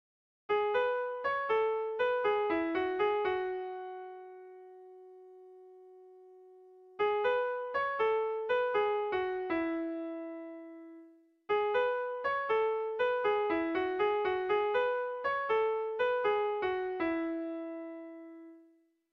Lauko handia (hg) / Bi puntuko handia (ip)
AA